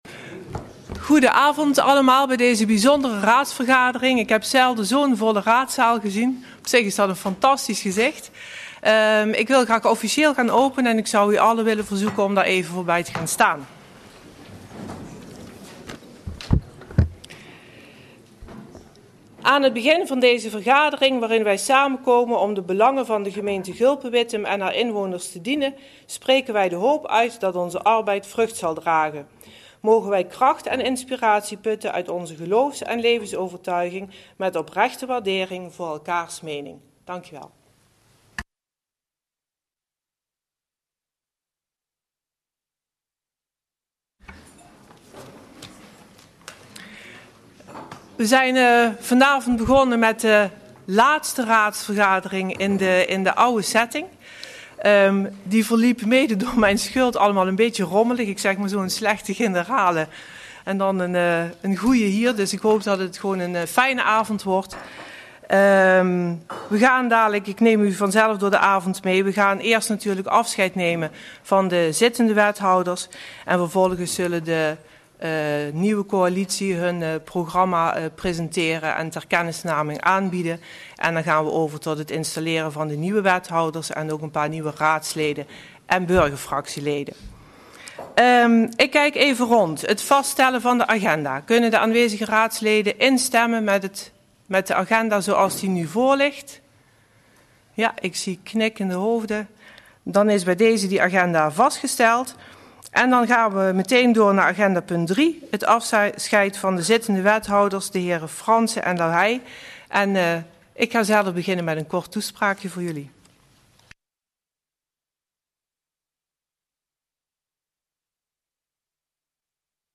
Agenda GulpenWittem - Raadsvergadering Benoeming wethouders donderdag 31 mei 2018 20:00 - 22:30 - iBabs Publieksportaal
Locatie Raadzaal